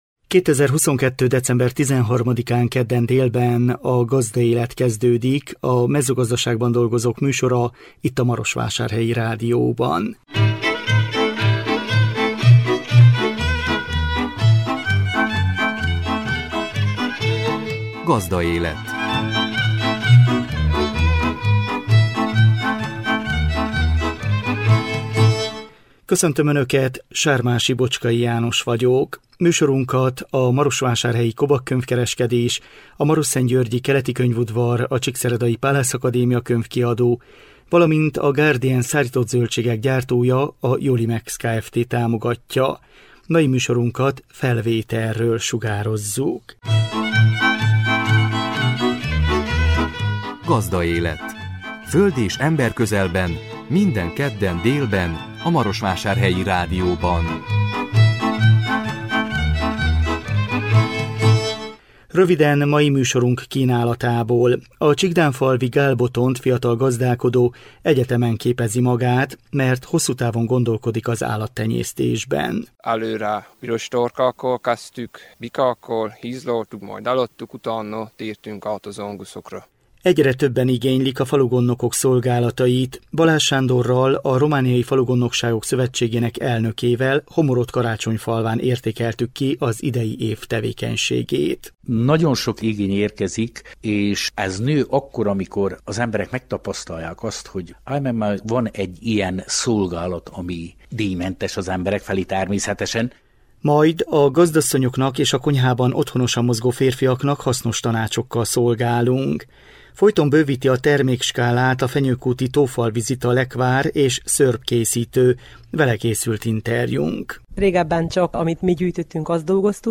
Vele készült interjúnk.